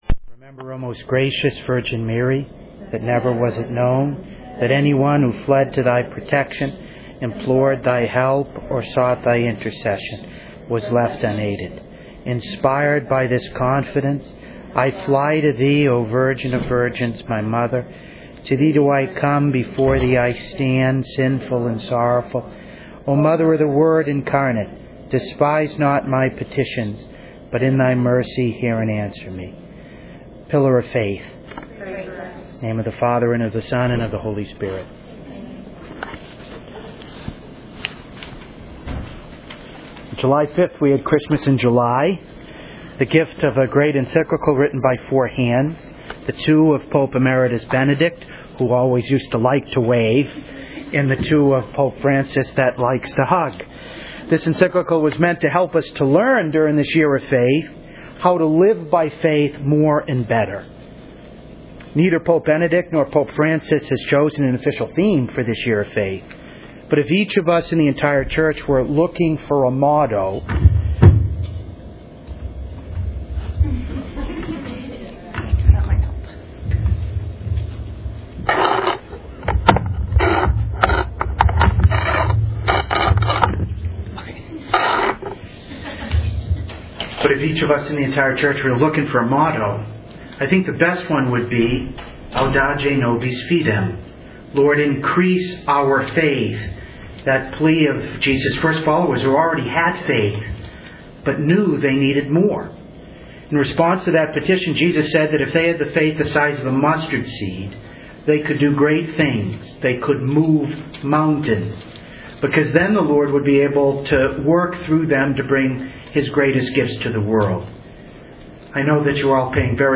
To listen to a recording of this talk and the question-and-answer period afterward, please click below: